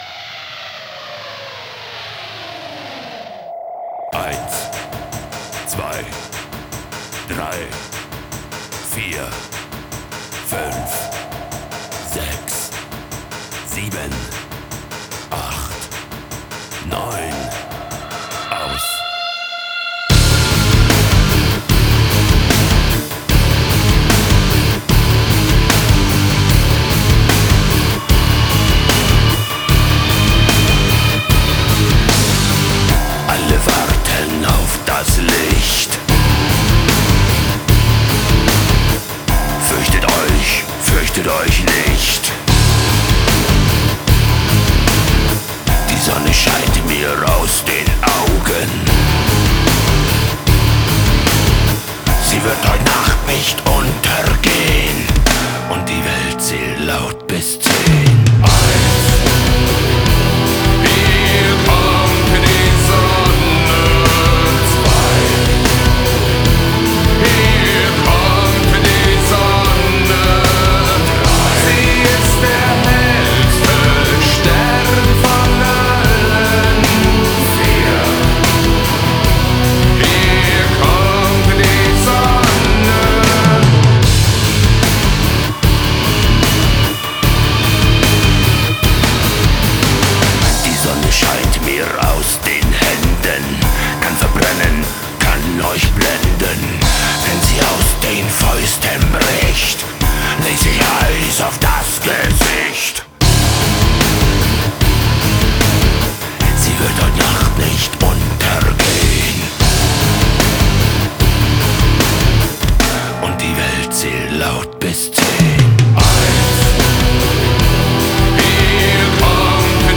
Зарубежный Рок